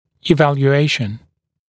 [ɪˌvælju’eɪʃn][иˌвэлйу’эйшн]оценка, оценивание, определение